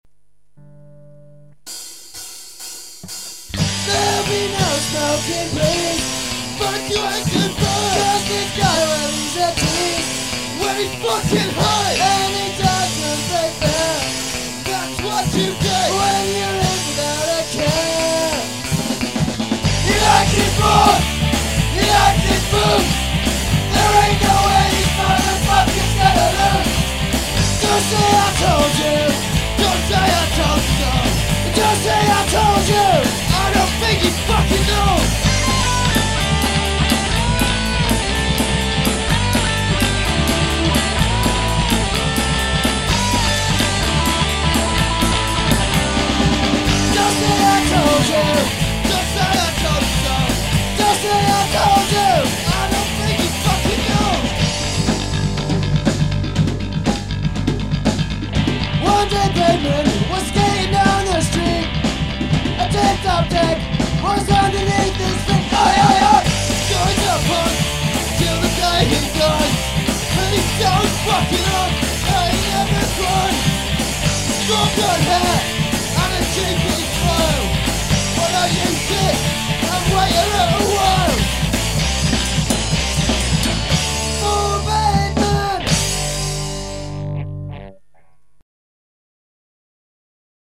Ska
Punk